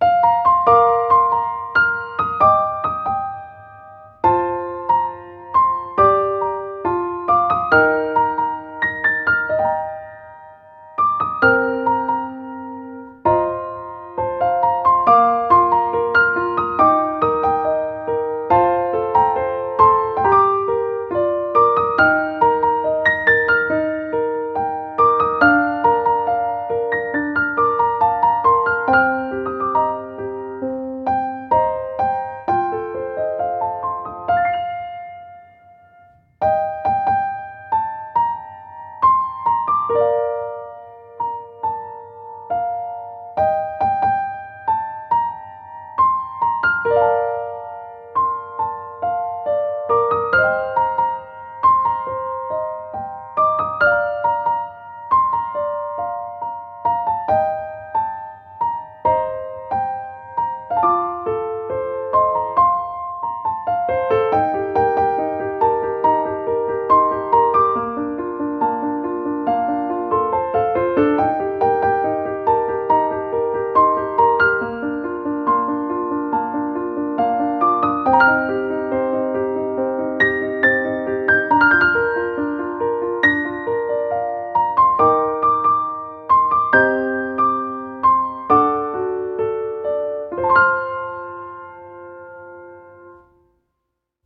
ogg(R) 楽譜 やさしい ゆったり 癒し
高音のメロディが染みわたる。